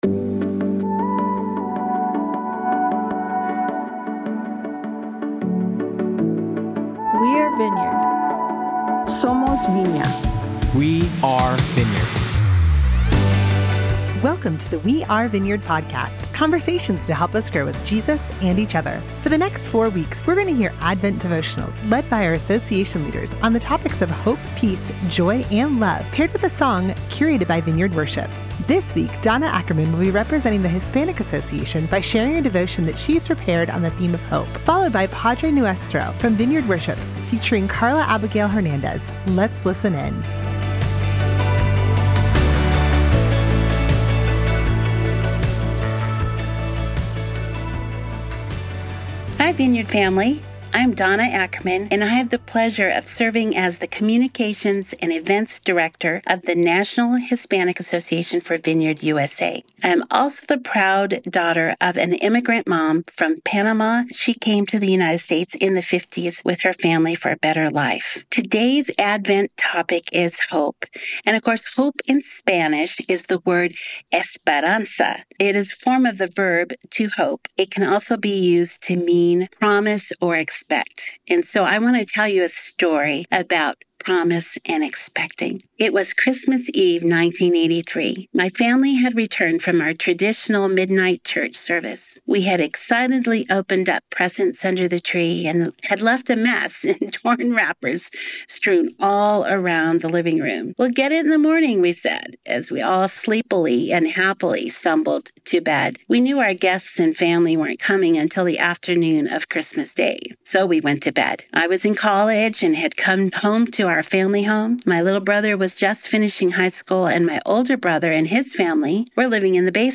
Welcome to the We Are Vineyard podcast. In celebration of the Advent season, you will hear a short devotion each week specially crafted for you by a member of one of Vineyard USA’s Associations, followed by a song curated by the Vineyard Worship team.